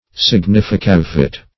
Significavit \Sig`ni*fi*ca"vit\, n. [L., (he) has signified,